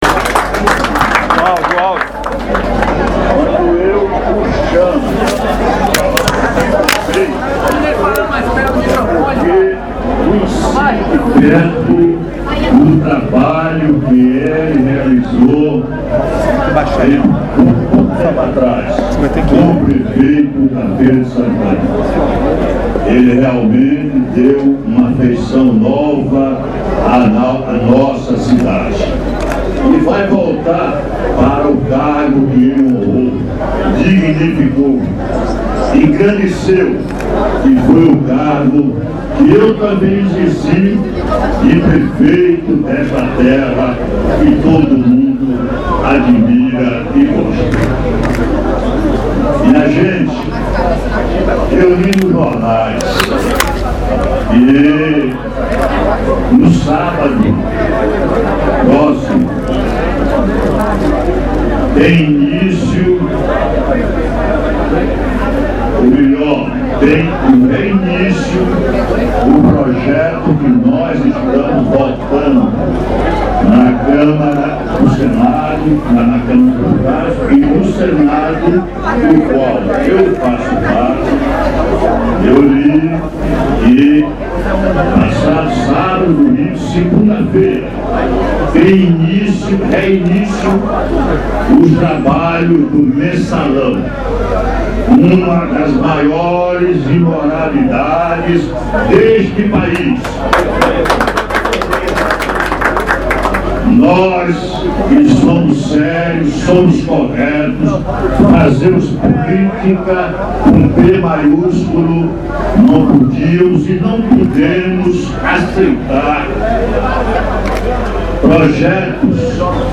O senador João Durval Carneiro (PDT-BA) declarou na manhã de hoje (23/08/2012), na sede da coligação “O Trabalho Vai Voltar”, em Feira de Santana, apoio ao candidato do Democratas José Ronaldo de Carvalho.
Como se quisesse passar uma mensagem subliminar durante o discurso, alfinetando petistas, João Durval repetia insistentemente que o “Mensalão tinha que ser extirpado da vida pública”.
|titles=Discurso de João Durval sobre apoio de José Ronaldo]
Discurso-de-Joao-Durval-sobre-apoio-de-Jose-Ronaldo.mp3